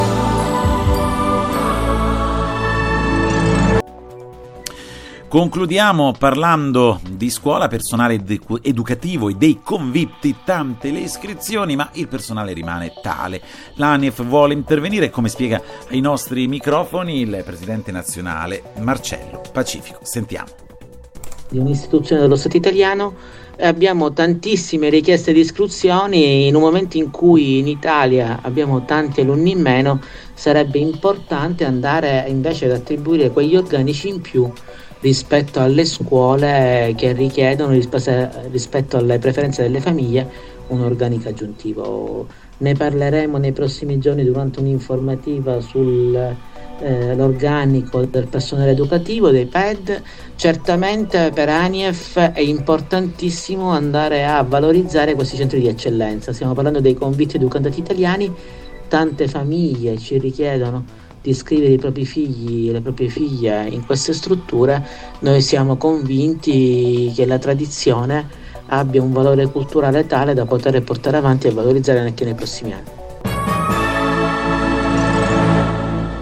Ai microfoni della